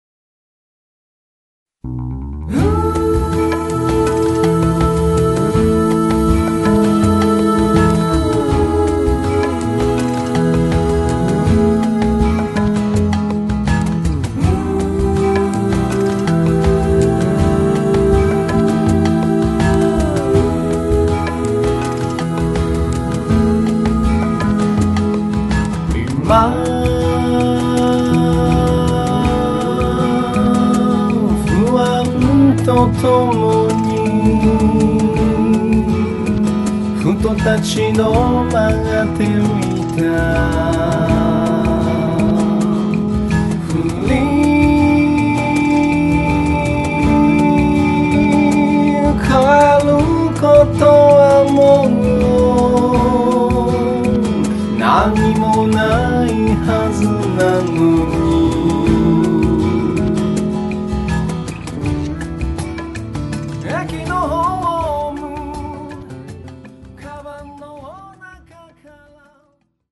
ソロ・ユニット
ベットルーム宅録CD
作っているとき民族音楽にハマってた、その影響が出ている。